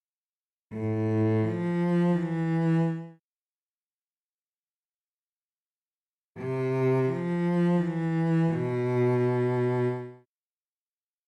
描述：String Loop
标签： 85 bpm Hip Hop Loops Strings Loops 1.90 MB wav Key : Unknown
声道立体声